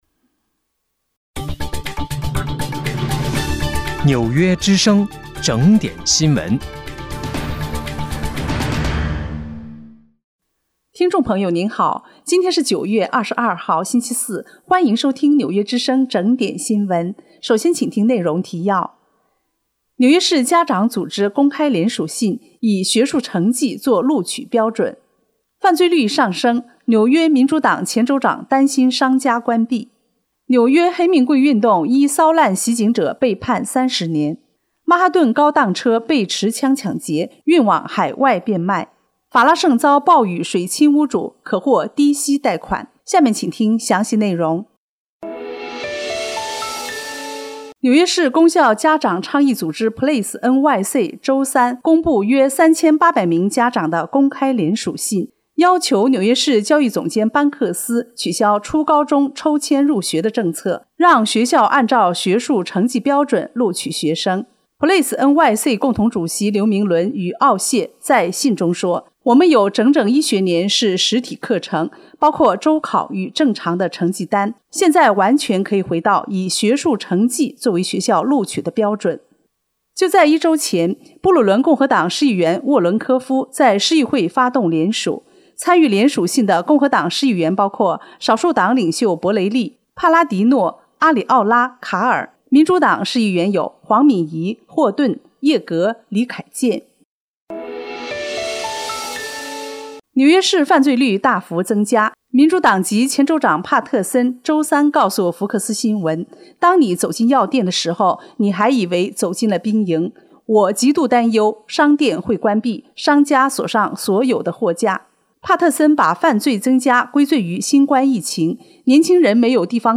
9月22号（星期四）纽约整点新闻